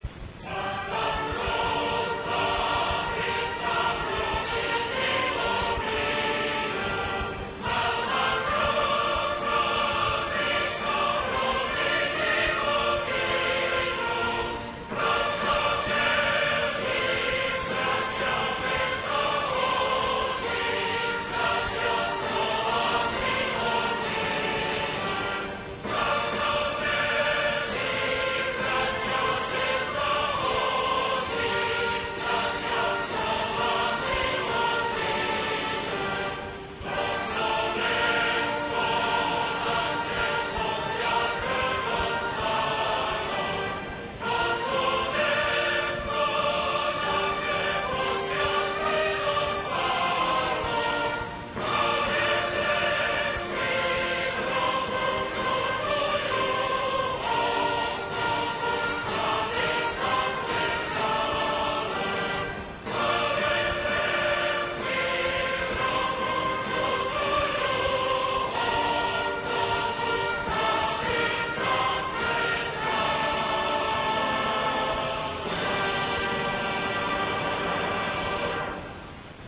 歌唱版RA